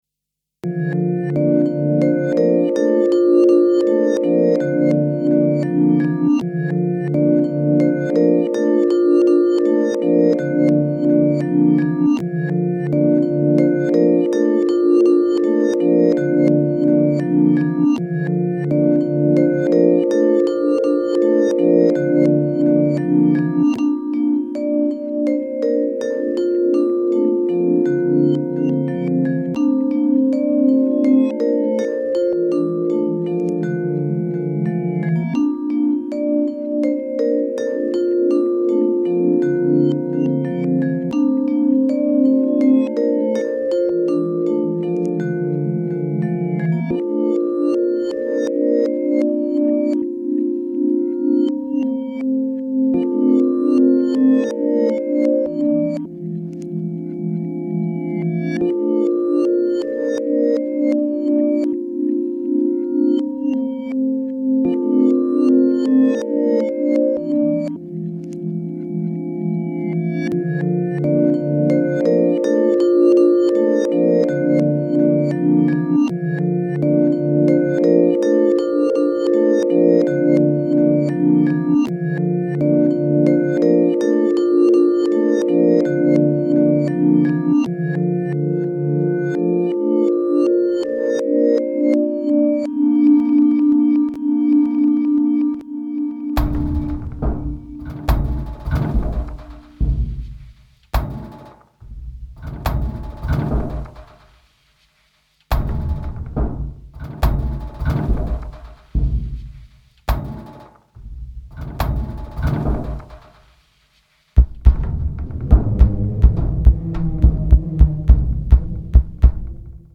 15 tracks, fifty minutes of soothing sounds.